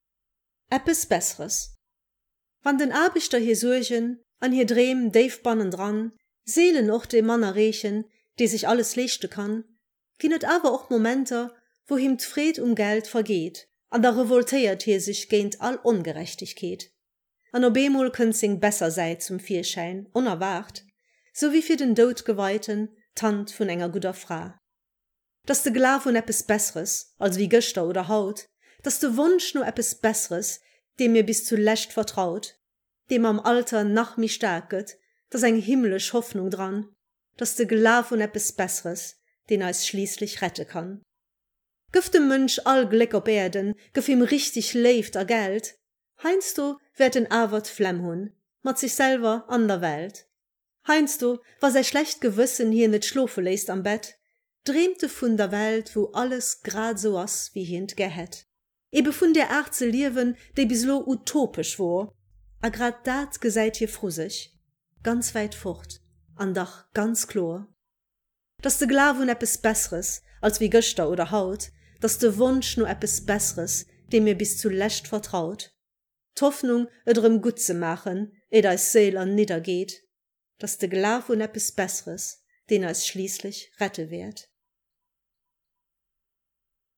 geschwate Versioun.